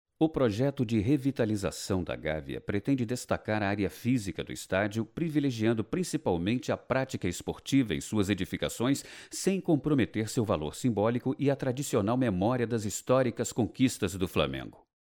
Masculino
Narração - Documentário Nova Sede do Flamengo
Voz Madura